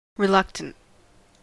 Ääntäminen
Synonyymit unwilling disinclined averse grudging loath restiff Ääntäminen : IPA : /ɹɪˈlʌk.tənt/ US : IPA : [ɹɪˈlʌk.tənt] Haettu sana löytyi näillä lähdekielillä: englanti Käännöksiä ei löytynyt valitulle kohdekielelle.